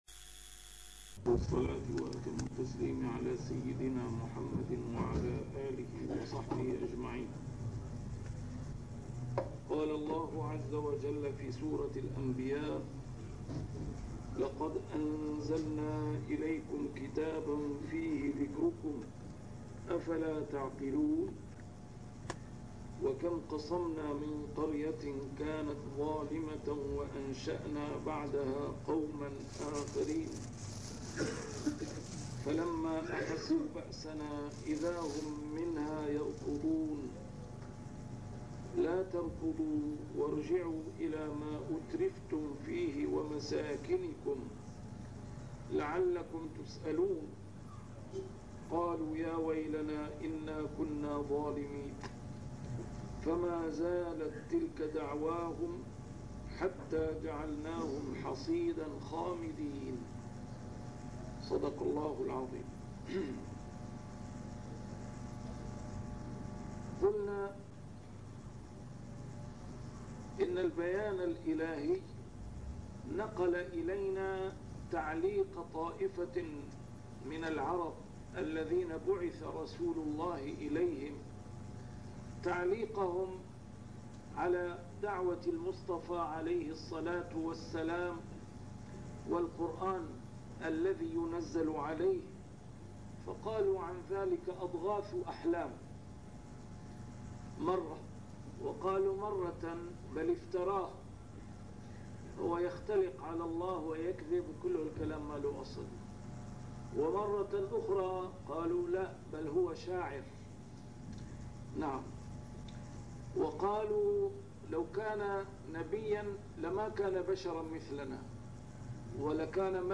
A MARTYR SCHOLAR: IMAM MUHAMMAD SAEED RAMADAN AL-BOUTI - الدروس العلمية - تفسير القرآن الكريم - تسجيل قديم - الدرس 83: الأنبياء 010